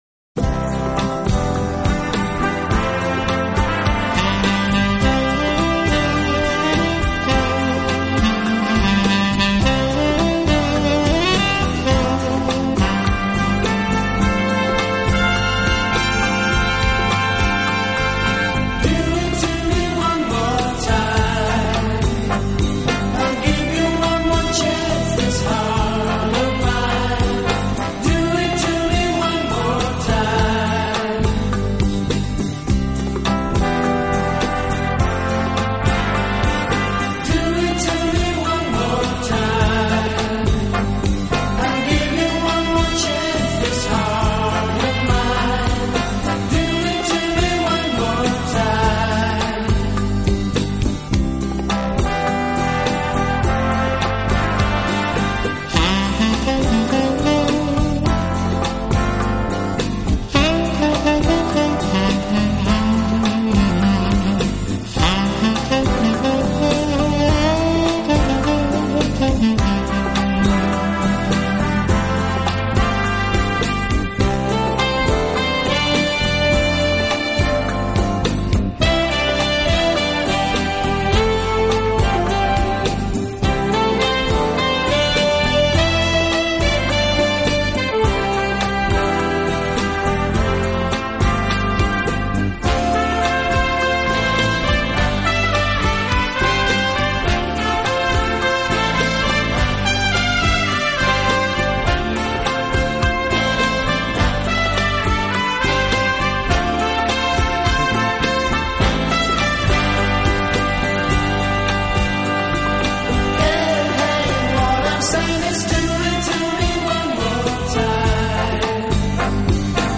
轻快、柔和、优美，带有浓郁的爵士风味。
Rumba